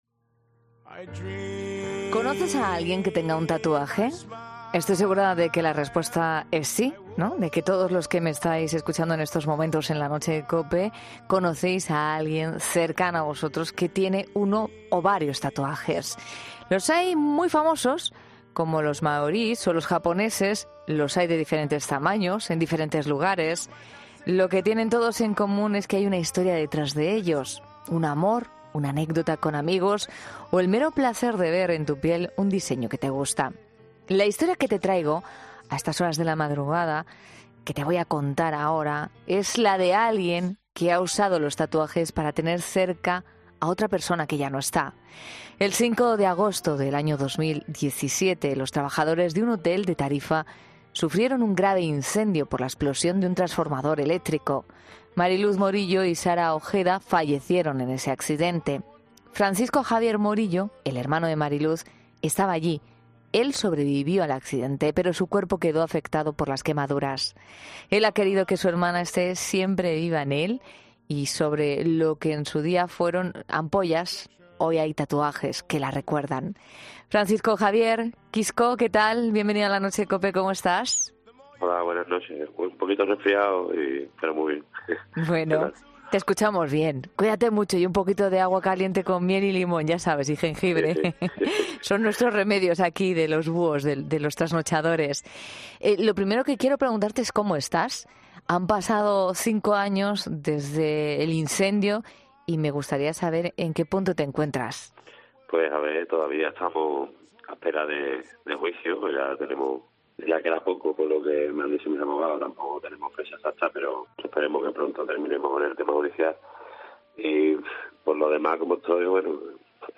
En los micrófonos de COPE ha contado cómo surgió el hecho de dedicarse a esta profesión y cómo se siente cuando trata con clientes con historias realmente duras a sus espaldas.